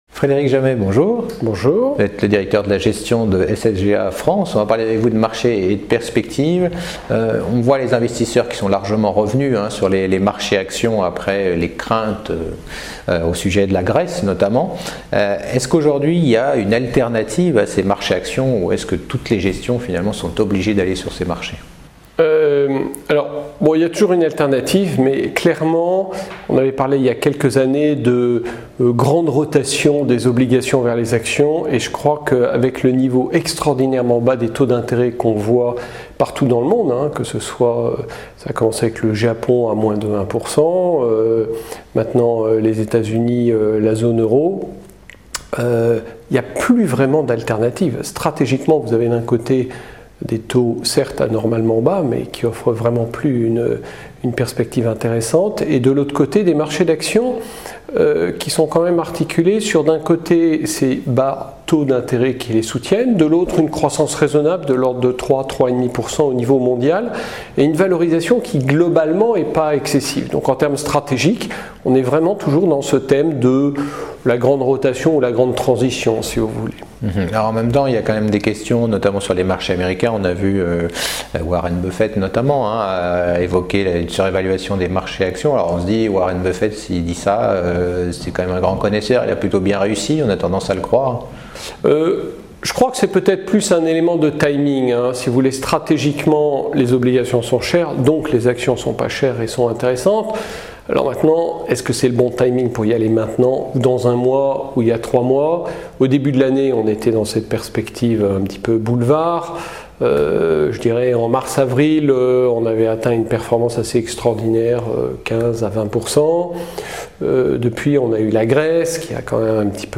Revue de détails des sujets qui posent question aux investisseurs avec mon invité